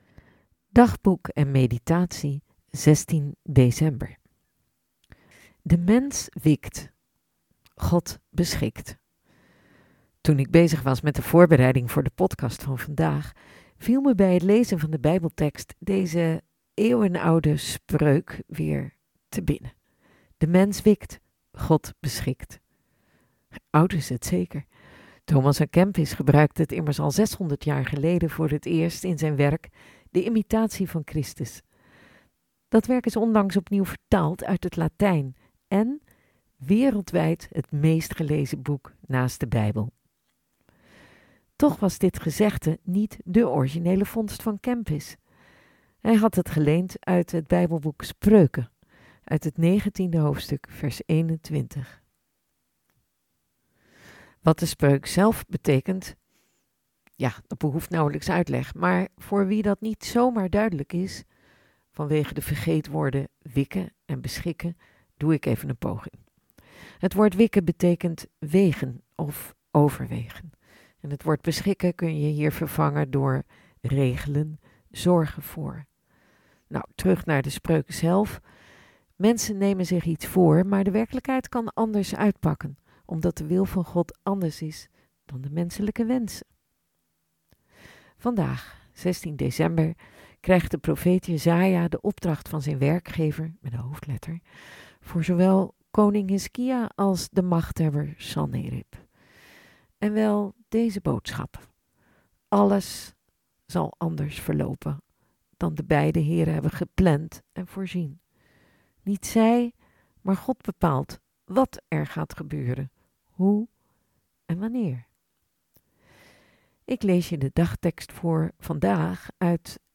Ik lees je de dagtekst voor vandaag uit het bijbelboek Jesaja, hoofdstuk 37, de verzen 33-35